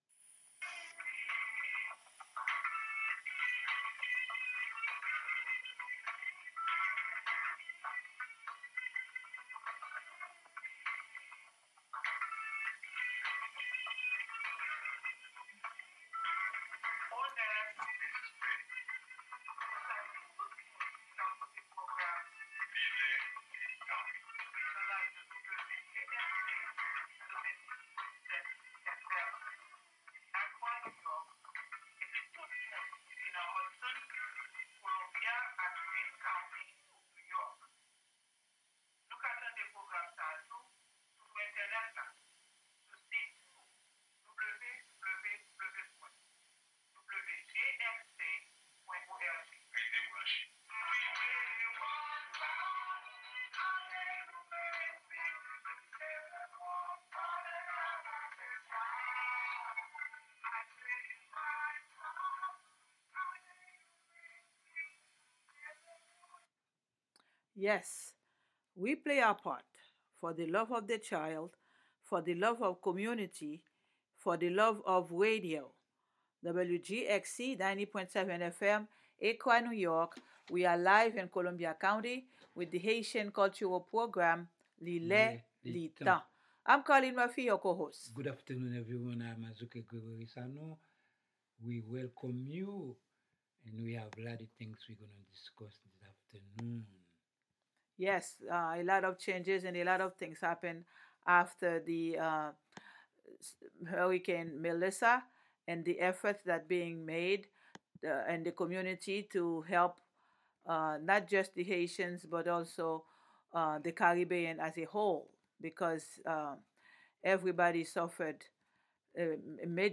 The show's mission is to promote Haitian language, music, arts, and culture; to raise awareness and to discuss Haiti’s history and its relevance to today’s world; and to share news on current events happening here in the counties and in Haiti. Broadcast live from Columbia County, New York.